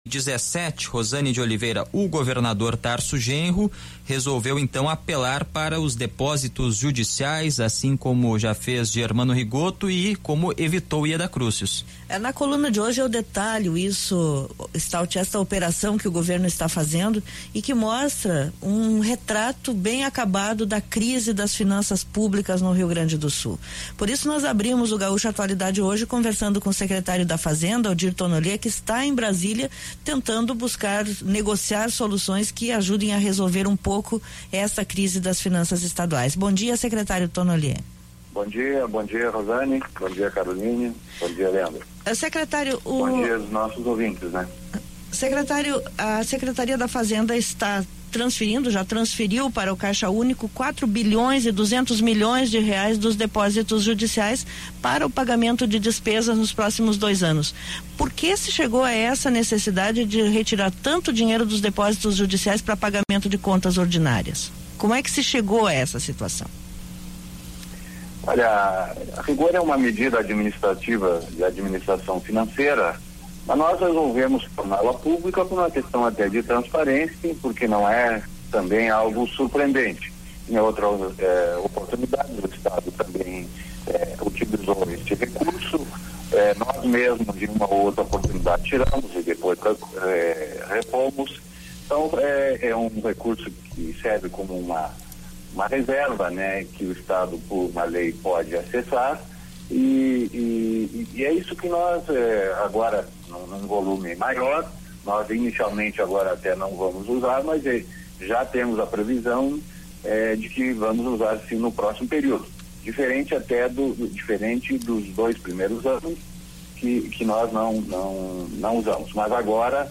Afocefe Sindicato - Informa��o - Not�cias - Entrevista com o secret�rio da Fazenda, Odir Tonollier, sobre as finan�as do Estado